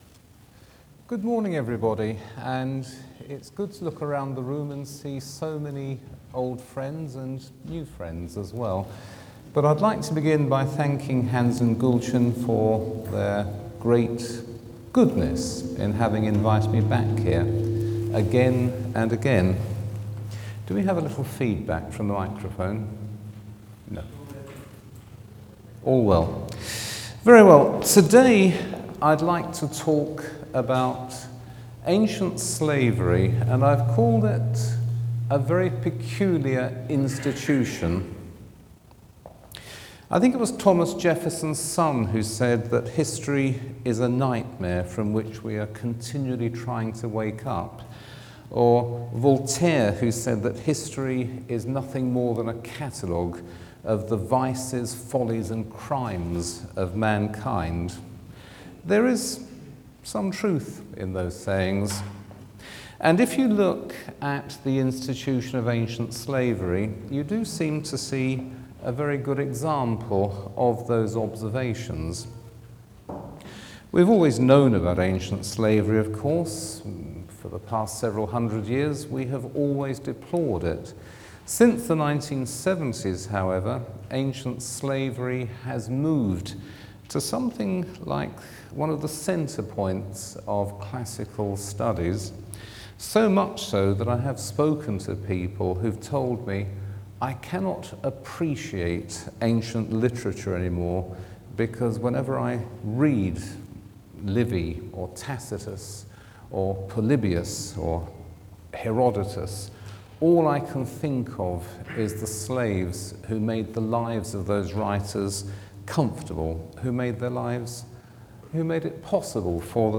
This talk is from the recently-concluded 19th annual PFS 2025 Annual Meeting (Sep. 18–23, 2025, Bodrum, Turkey).